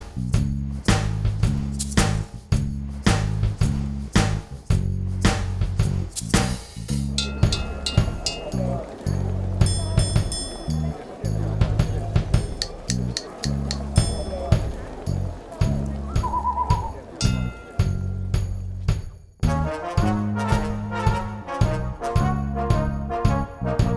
Minus Rhythm Guitar Pop (1960s) 2:45 Buy £1.50